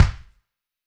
B.B KICK 3.wav